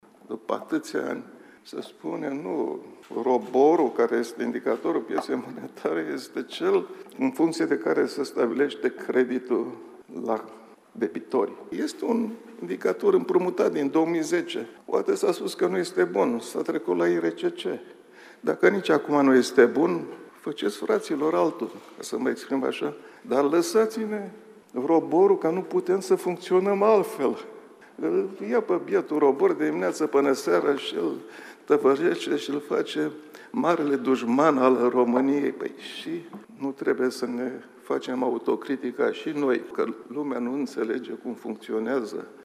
Guvernatorul Băncii Naționale a vorbit azi despre confuziile din societate legate de politicile monetare, la Forumul Educației Financiare, și s-a referit la raportul Consiliului Concurenței referitor la modul în care s-a stabilit indicele ROBOR – dobânda pentru cei care aveau credite în lei, înlocuit acum cu IRCC.